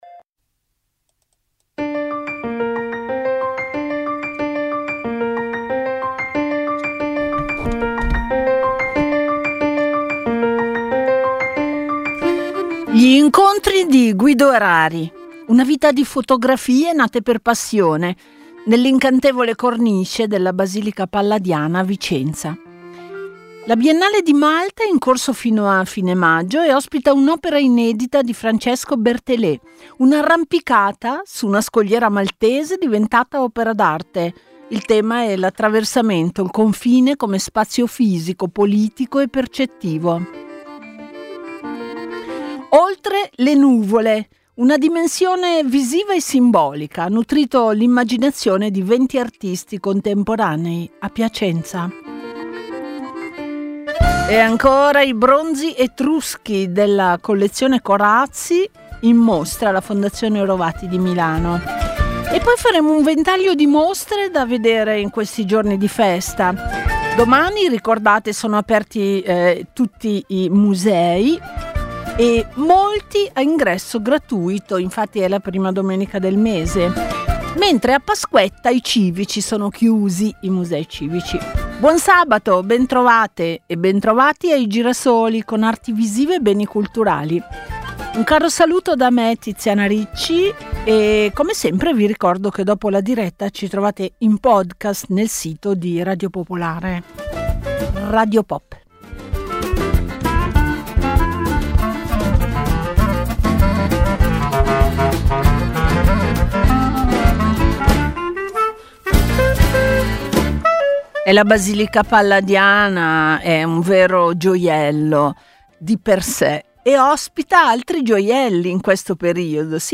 Ogni sabato alle 13.15, il programma esplora eventi culturali, offre interviste ai protagonisti dell'arte, e fornisce approfondimenti sui critici e sui giovani talenti. L’obiettivo è rendere accessibile il significato delle opere e valutare la qualità culturale degli eventi, contrastando il proliferare di iniziative di scarso valore e valutando le polemiche sulla politica culturale.